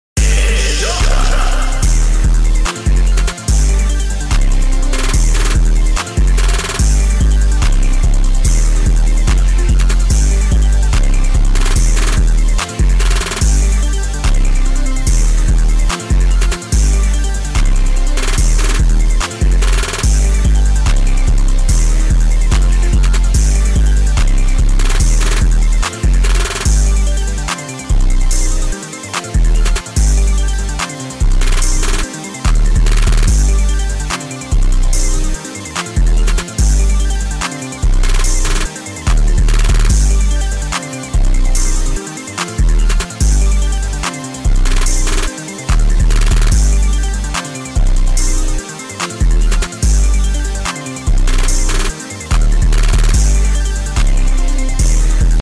🌟🌟 new sonic type beat